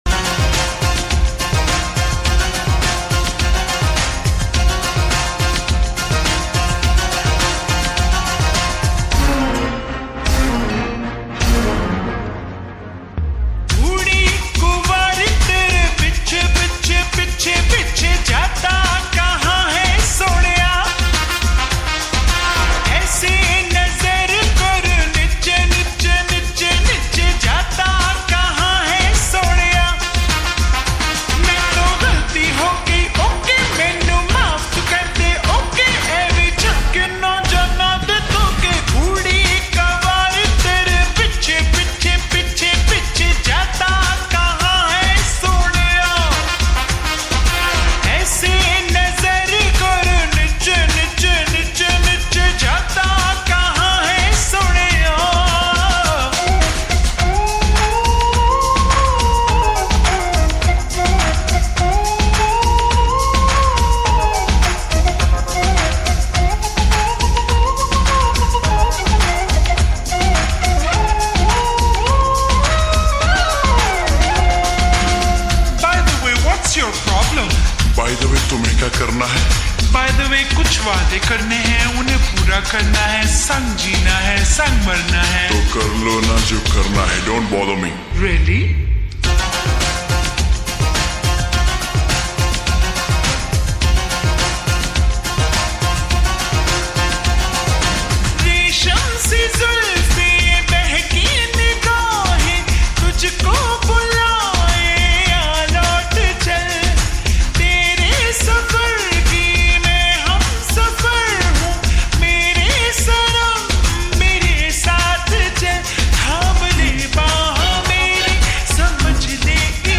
[SLOWED+REVERB]